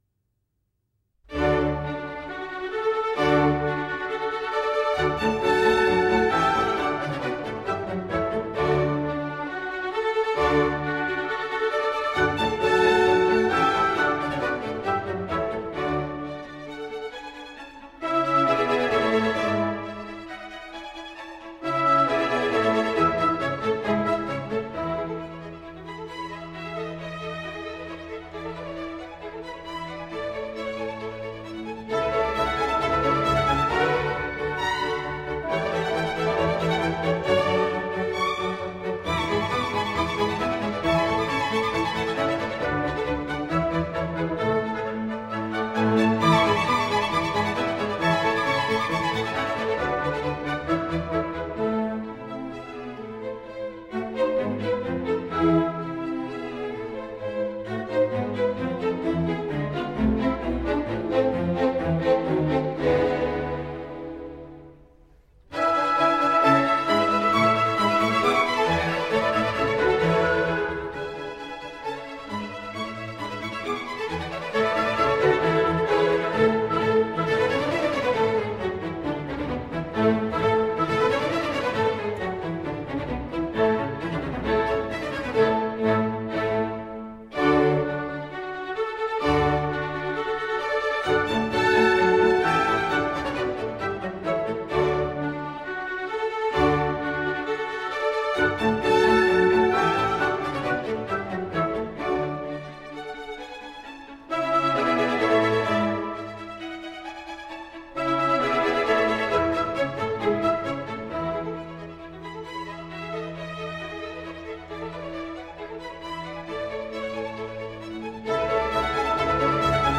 Classical serenade
Serenades are typically calm, light music Mozart - 02 - Serenade in D K100 - A